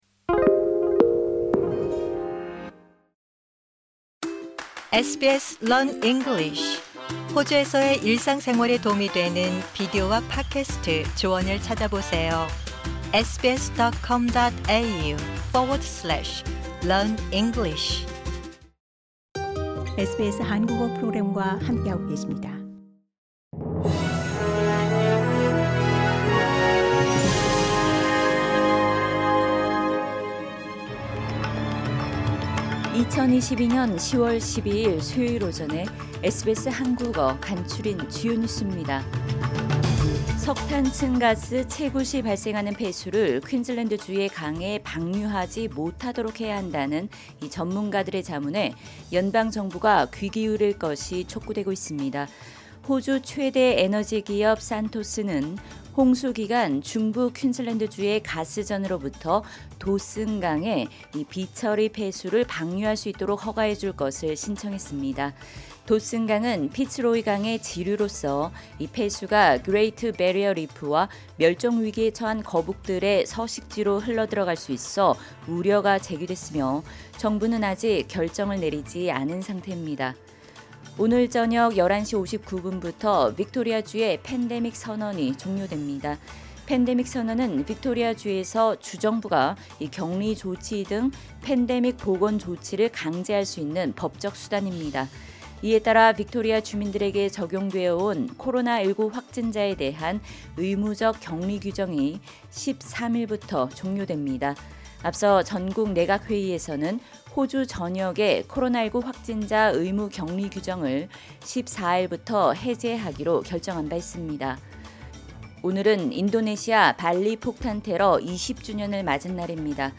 SBS 한국어 아침 뉴스: 2022년 10월 12일 수요일